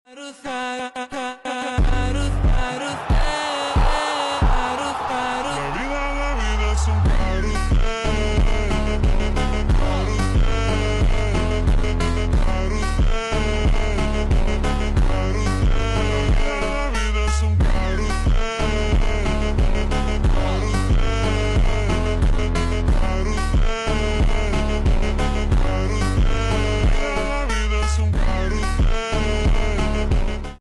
Ronaldo commentary 🔥☠